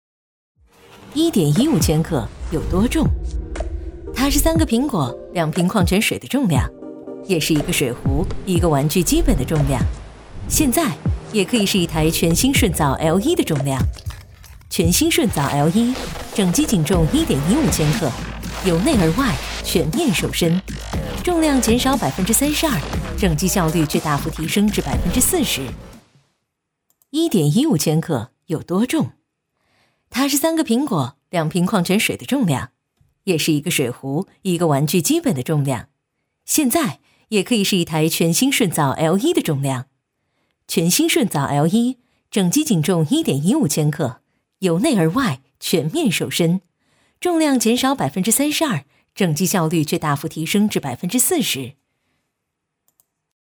科技感配音【海豚配音】
女29-科技感 时尚干脆
女29-科技感 时尚干脆 .mp3